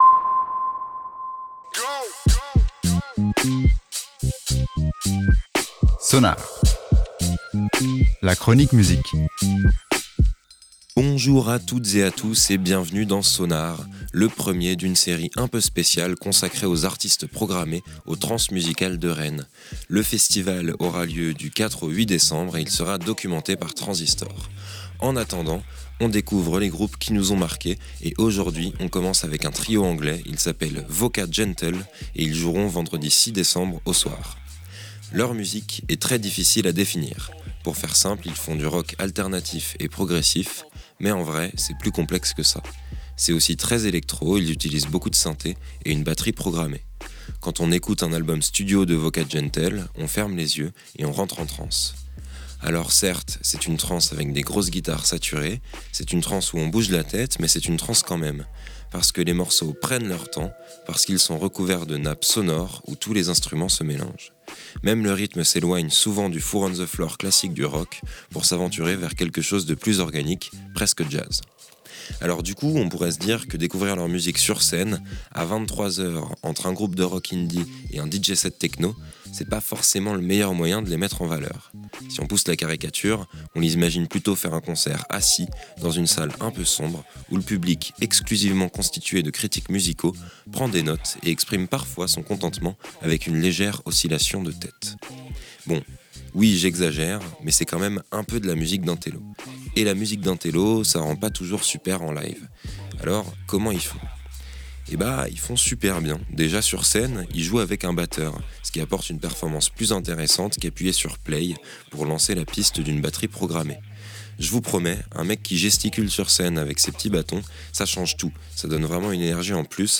Cela fait déjà dix ans que le groupe perfectionne son rock électronique.